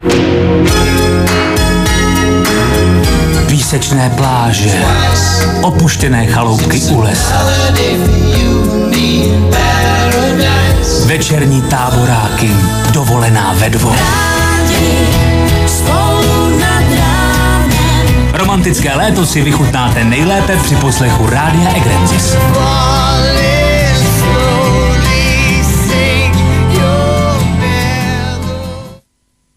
Self-promotion a letní grafika má u posluchačů navodit atmosféru táboráků, písečných pláží a dovolené ve dvou.